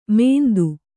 ♪ mēndu